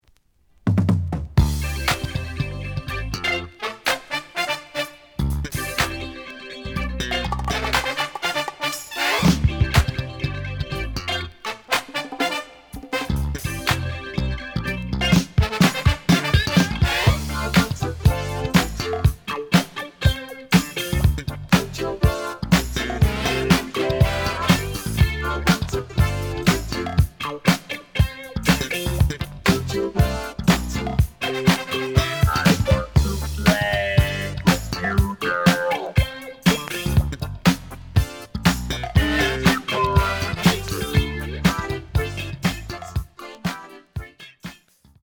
The audio sample is recorded from the actual item.
●Genre: Funk, 80's / 90's Funk